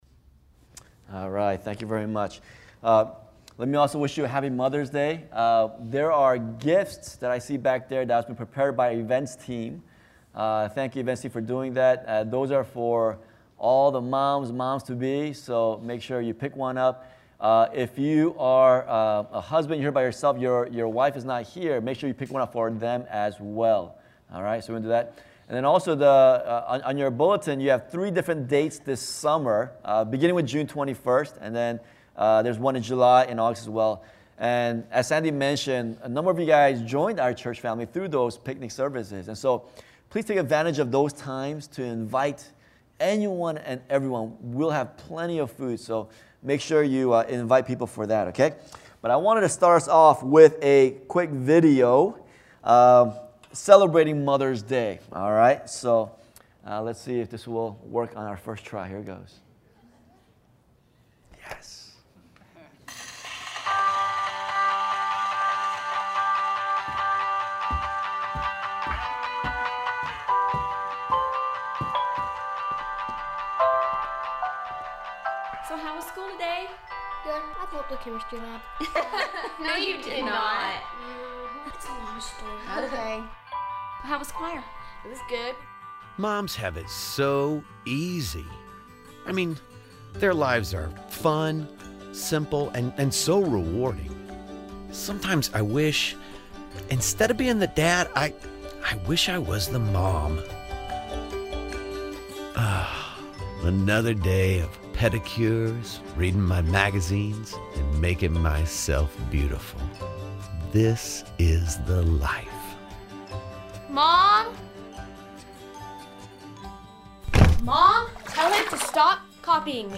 sermons - Church of the Beloved